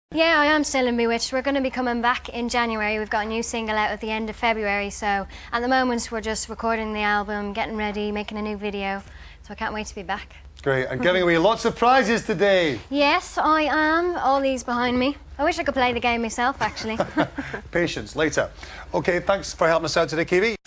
Keavy appeared as a guest presenter on ITV1`s show This Morning.
Some clips have been edited to remove dead air. All crackling/rustling is from the microphones rubbing on clothing.